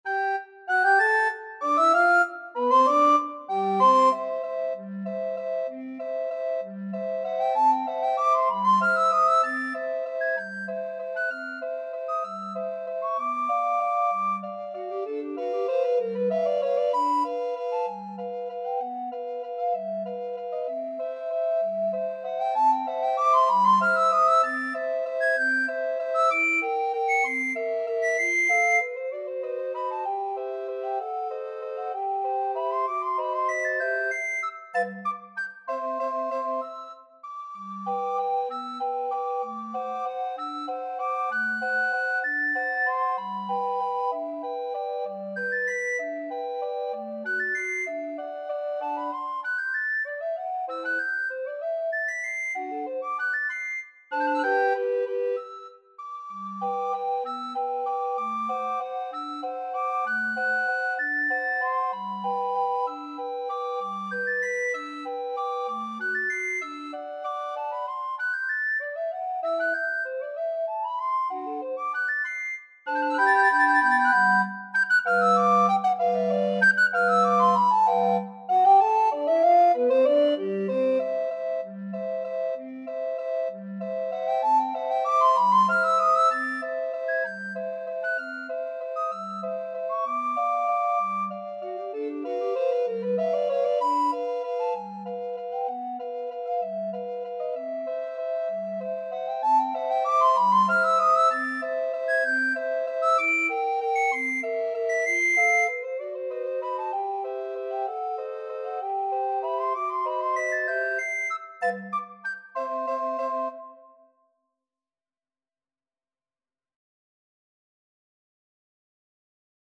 Soprano RecorderAlto RecorderTenor RecorderBass Recorder
Tempo di Waltz (.=c.64)
3/4 (View more 3/4 Music)
Classical (View more Classical Recorder Quartet Music)